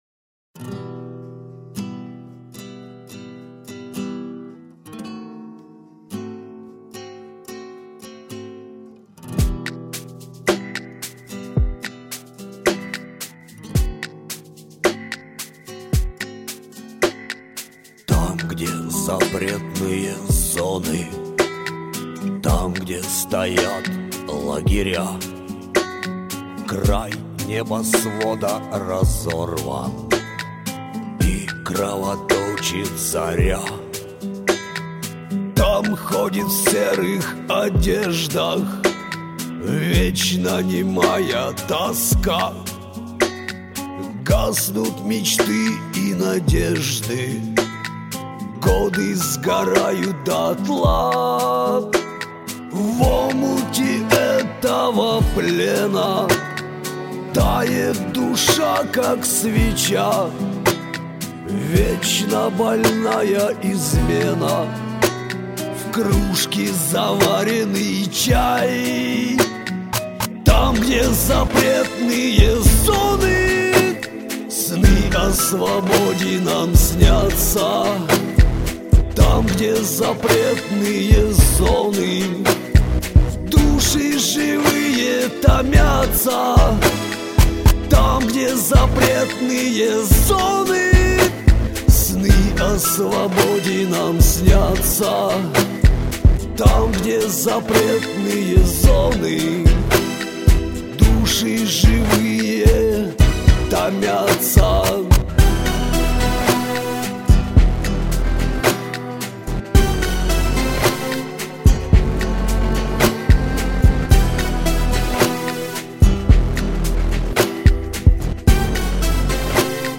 Привлекательная песня - грустью, красивой, светлой!